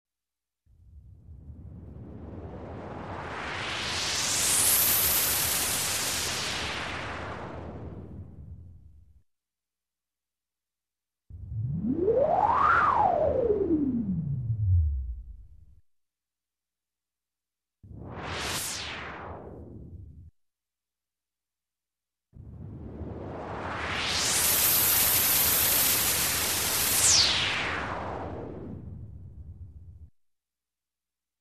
Электронный свист, космос